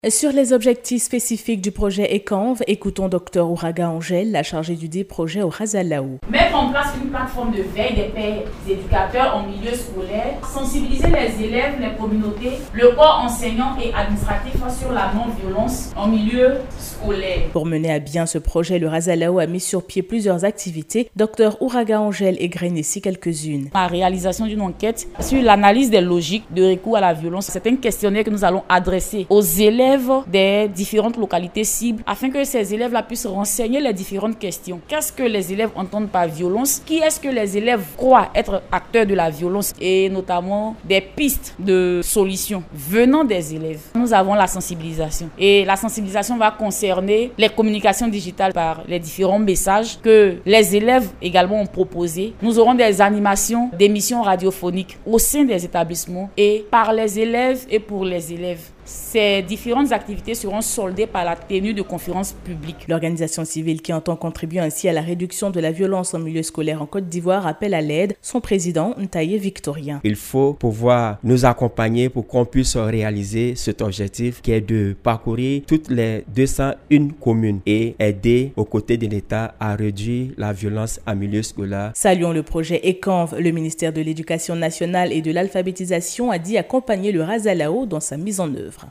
C’était le jeudi 20 octobre 2022, au cours d’une cérémonie à son siège sis à Adjamé.
Actualités